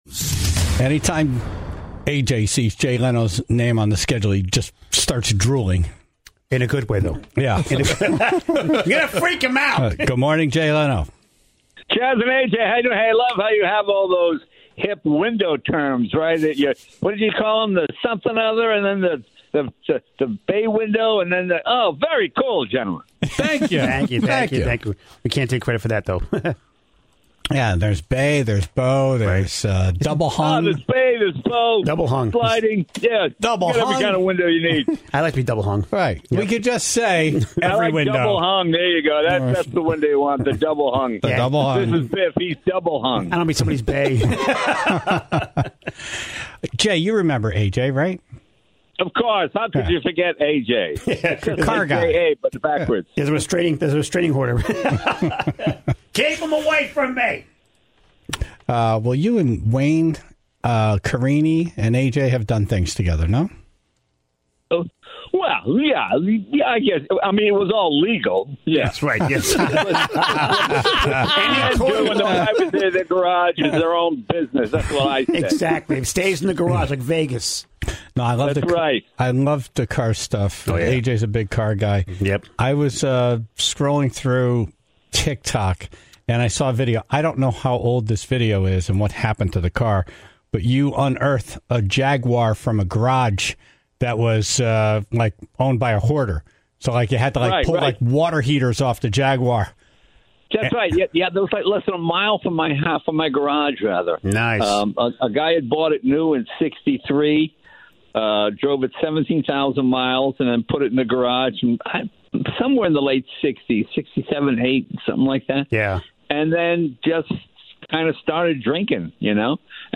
had Jay Leno on the phone this morning to talk about his show at Foxwoods tomorrow.